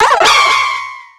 Cri de Viridium dans Pokémon X et Y.